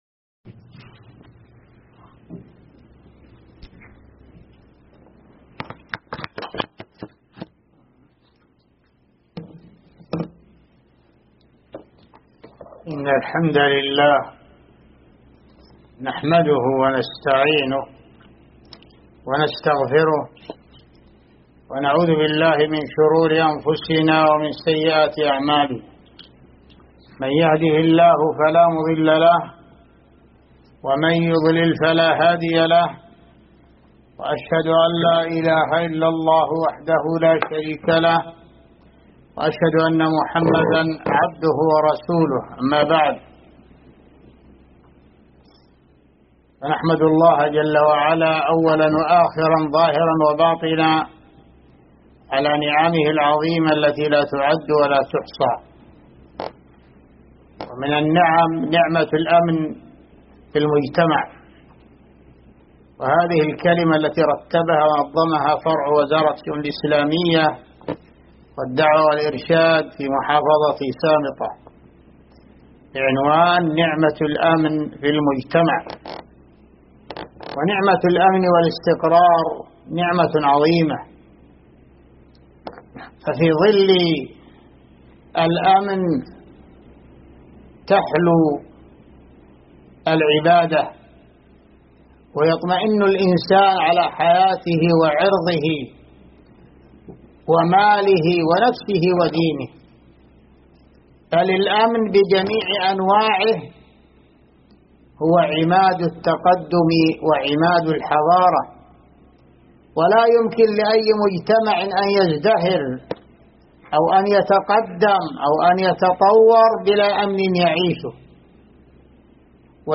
محاضرة
مسجد الشيخ بقرية النجامية